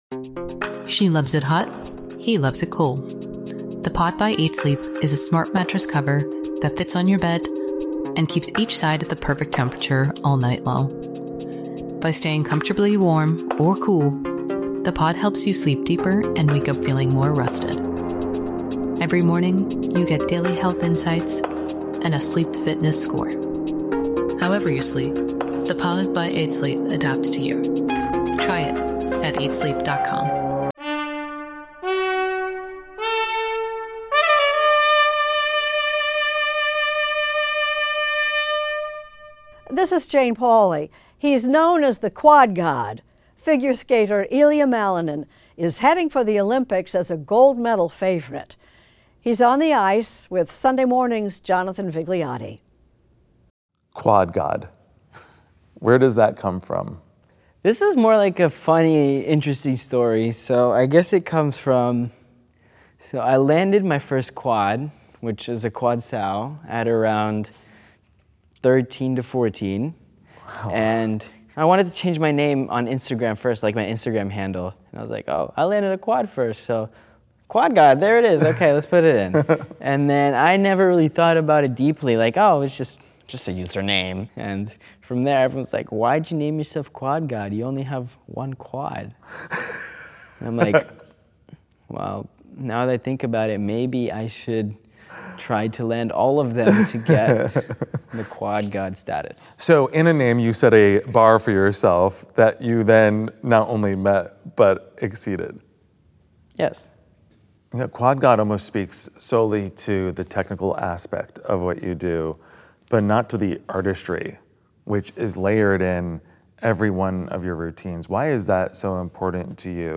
Extended Interview: Ilia Malinin
Figure skating superstar Ilia Malinin talks with correspondent Jonathan Vigliotti in advance of the Winter Olympics. He talks about the artistry of his routines; having his parents (former Olympic skaters Roman Skorniakov and Tatiana Malinina) as coaches; and landing a quad axel.